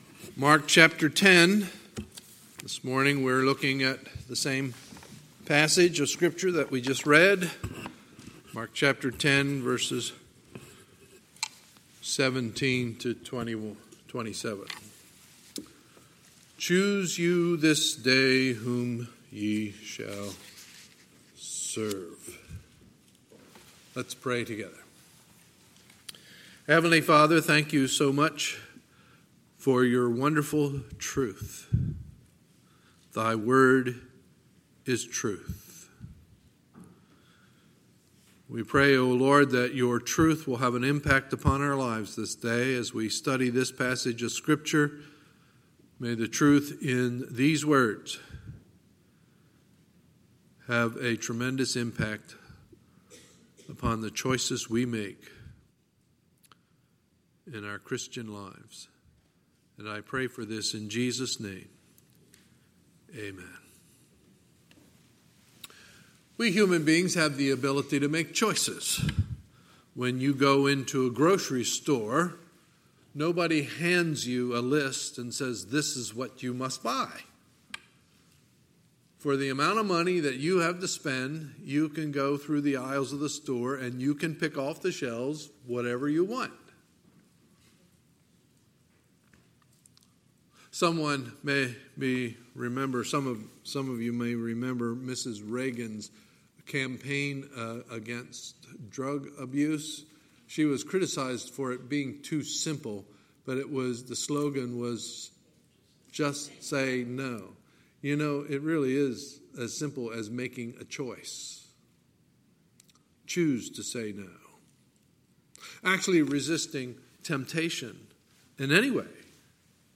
Sunday, August 18, 2019 – Sunday Morning Service